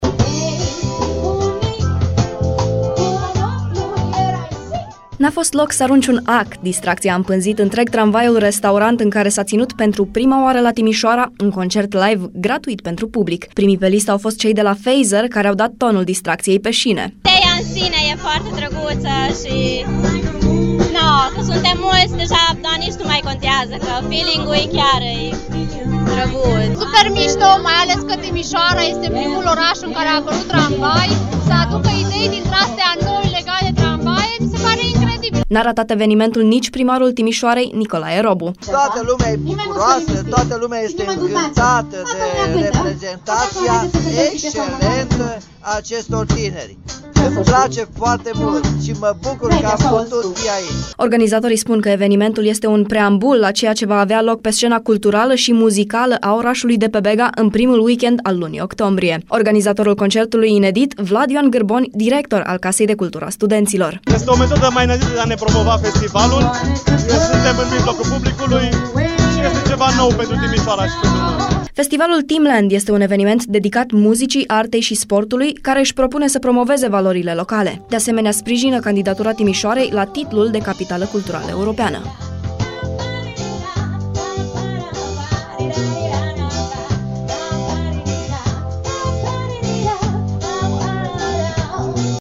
Primul concert ‘Live în tramvai’ s-a desfăşurat miercuri la Timişoara, cu zeci de spectatori şi cu trupe autohtone.
A urcat de pe traseu chiar şi primarul Timişoarei, Nicolae Robu, care a a şi cântat alături de artişti